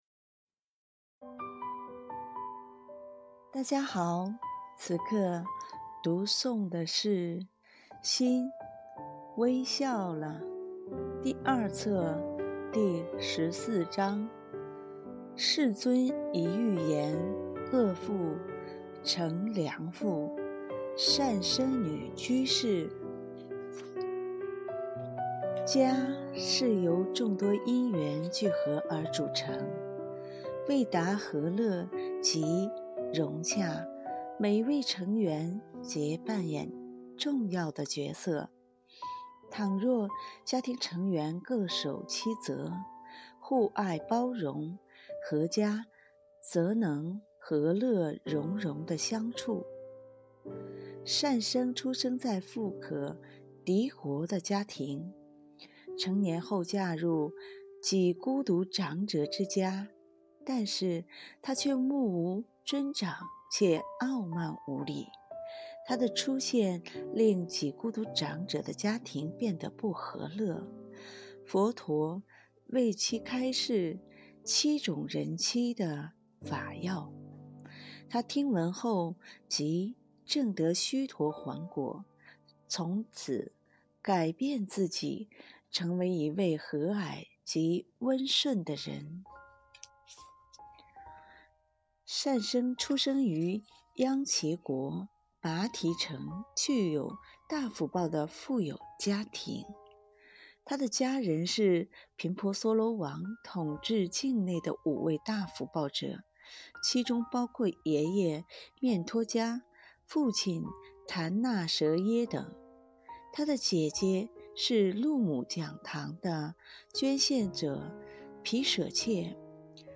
電子書： 《心微笑了》繁體版｜修訂版 PDF Download 《心微笑了》简体版｜修订版 PDF Download 有聲書： 世尊一諭言，惡婦成良婦——善生女居